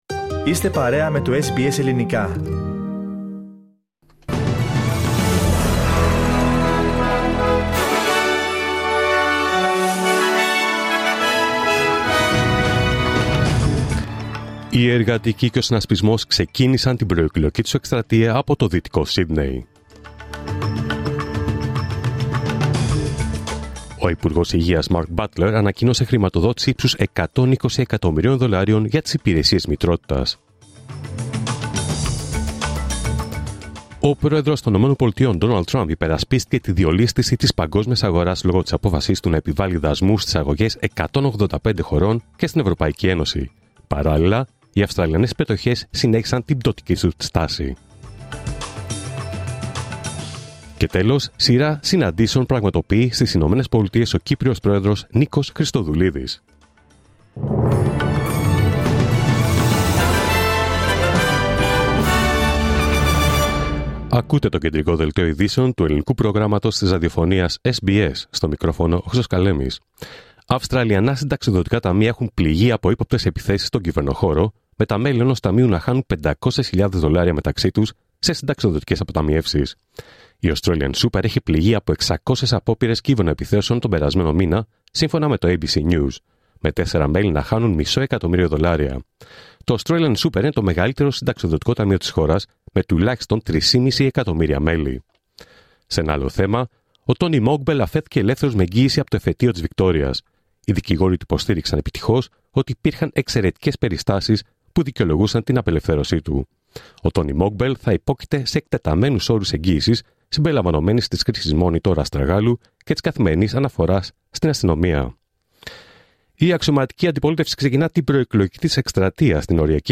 Δελτίο Ειδήσεων Παρασκευή 4 Απριλίου 2025